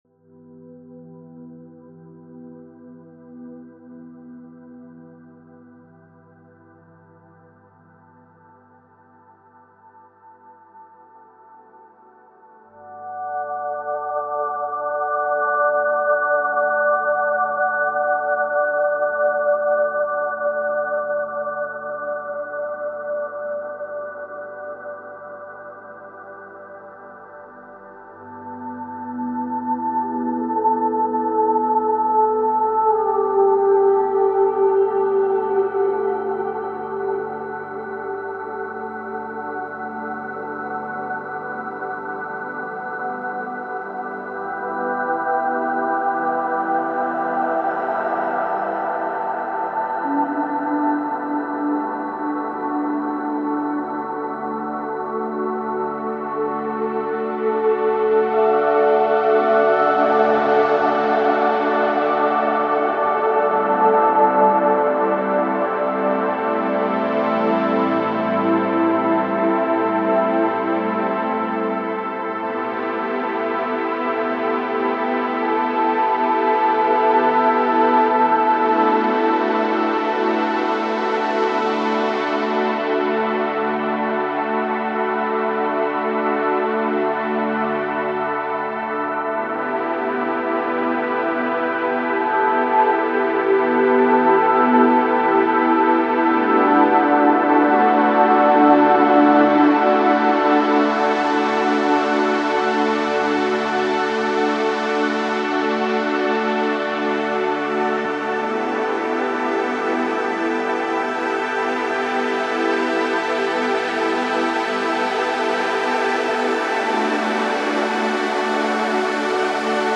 He mixes electronic and organic instruments to craft unique musical universes.
Cette musique est constituée de beaucoup d’éléments.